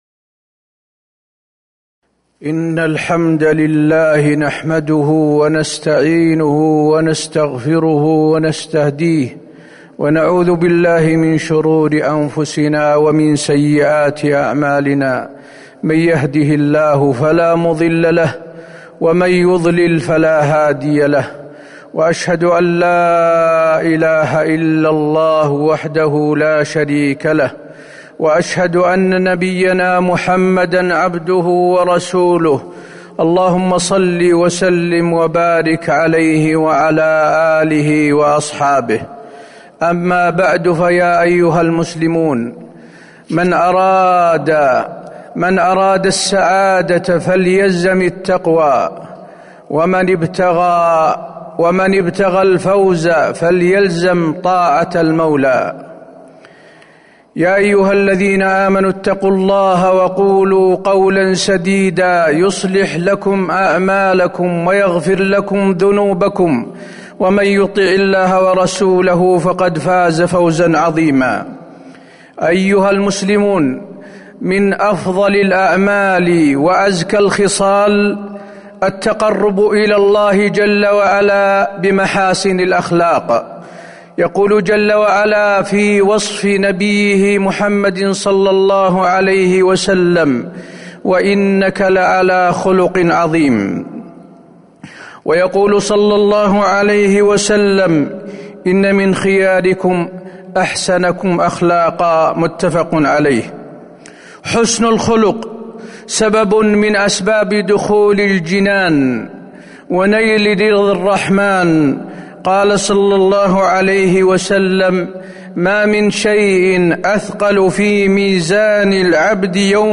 تاريخ النشر ٢٤ صفر ١٤٤٣ هـ المكان: المسجد النبوي الشيخ: فضيلة الشيخ د. حسين بن عبدالعزيز آل الشيخ فضيلة الشيخ د. حسين بن عبدالعزيز آل الشيخ حسن الخلق